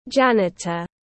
Người quét dọn tiếng anh gọi là janitor, phiên âm tiếng anh đọc là /ˈdʒænɪtər/.
Janitor /ˈdʒænɪtər/